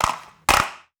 Skateboard Flip.wav